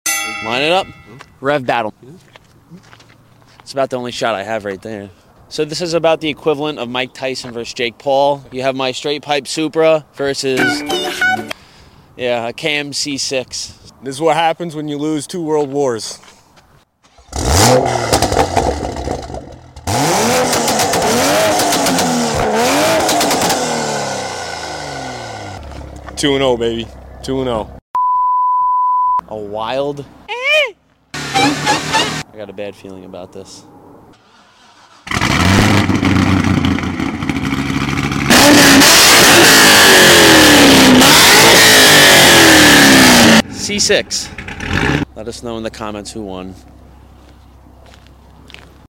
Cammed C6 Vette vs. Toyota sound effects free download
Cammed C6 Vette vs. Toyota Supra REV BATTLE 🔥